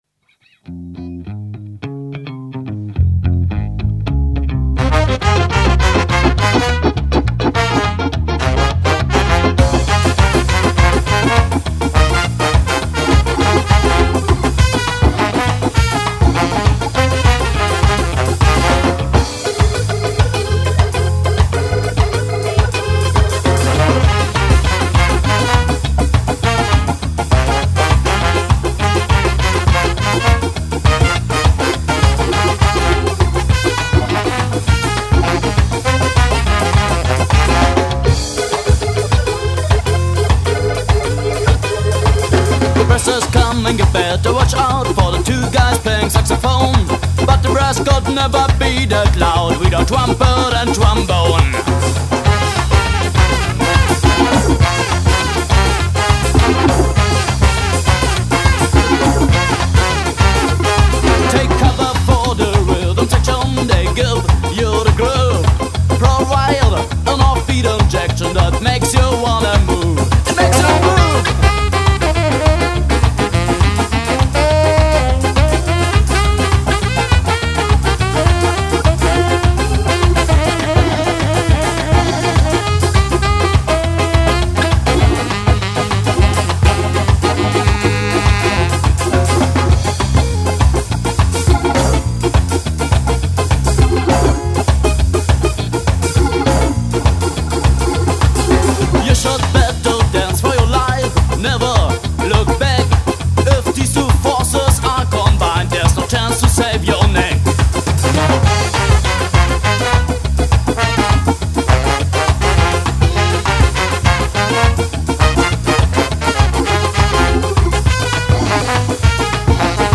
Ska since 1994 Ska und Jamaica gehören zusammen